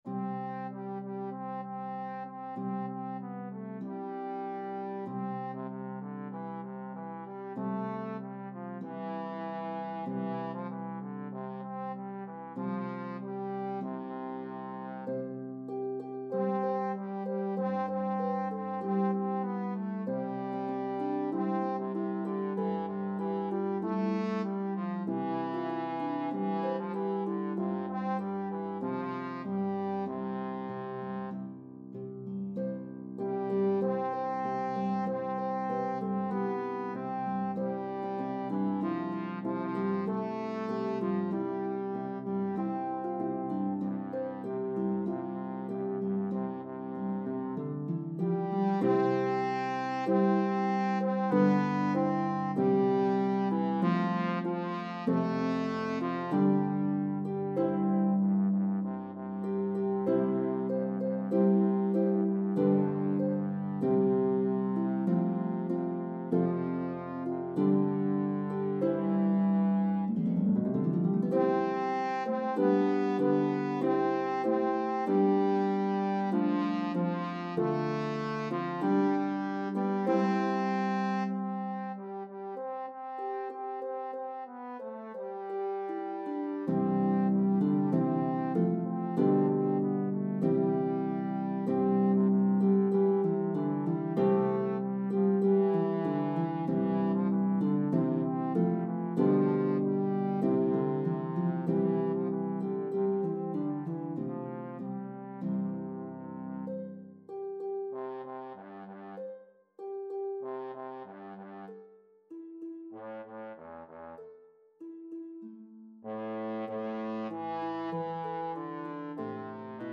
a medley of traditional English & Irish Carols